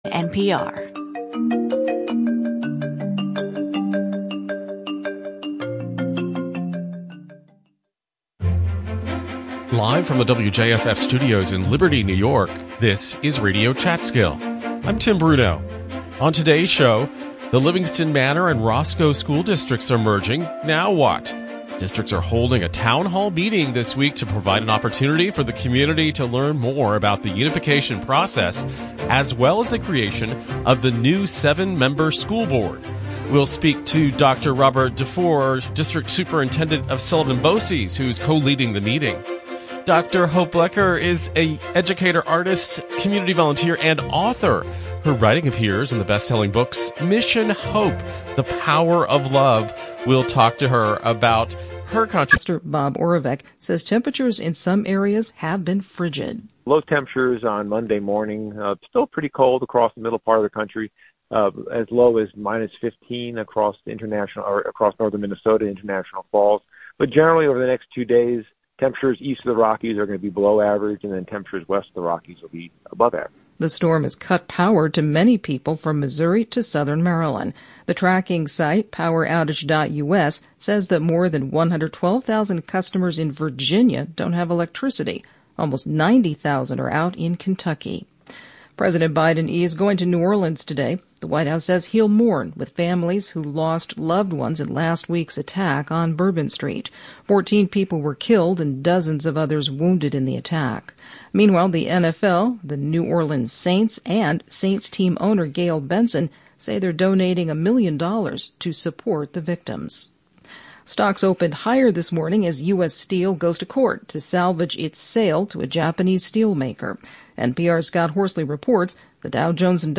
Live, local conversations focused on arts, history, and current news.